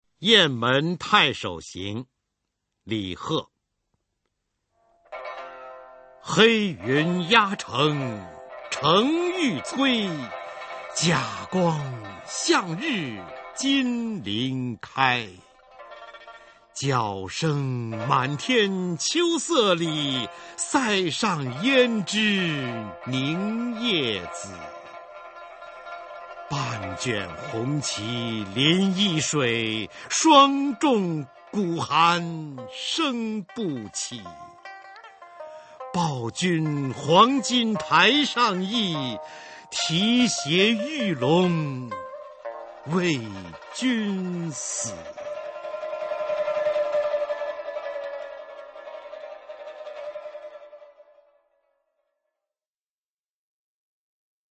[隋唐诗词诵读]李贺-雁门太守行（男） 配乐诗朗诵